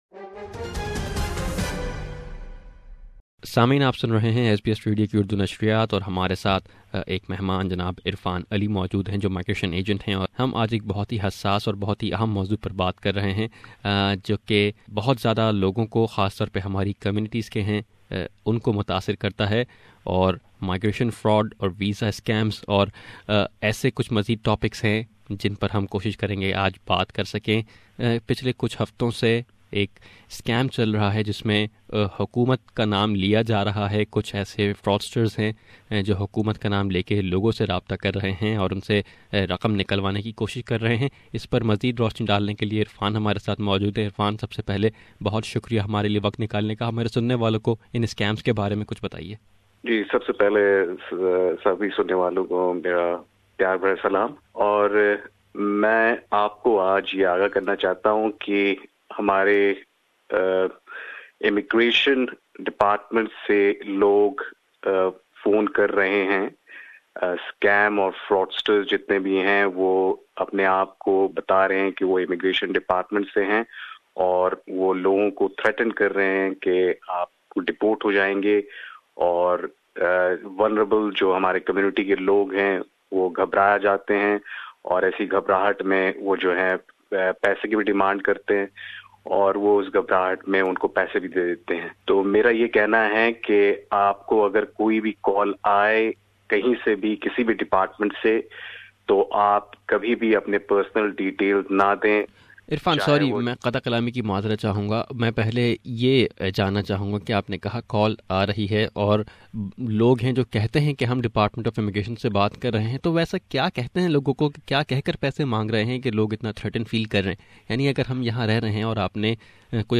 Migration agent